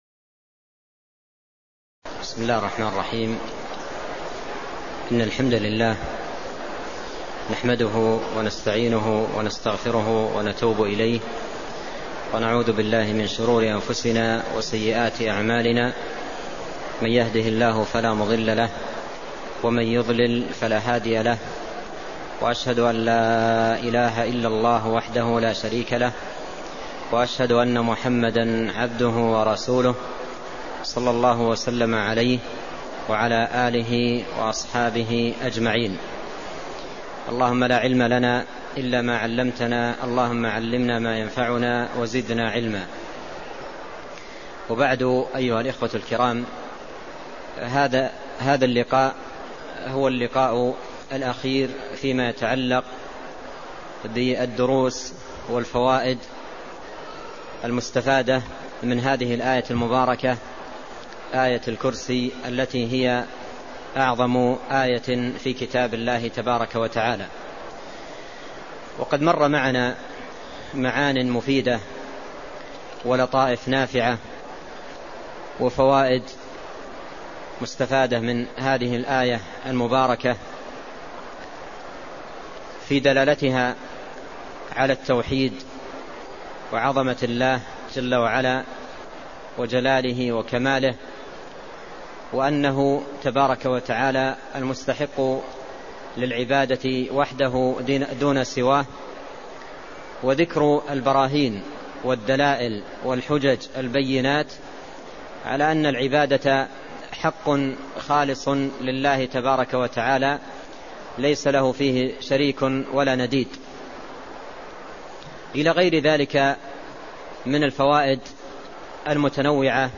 تفسير آية الكرسي - الدرس الأخير (027)
تاريخ النشر ١ رجب ١٤٢٧ هـ المكان: المسجد النبوي الشيخ: فضيلة الشيخ عبد الرزاق بن عبد المحسن البدر فضيلة الشيخ عبد الرزاق بن عبد المحسن البدر تفسير آية الكرسي - الدرس الأخير (027)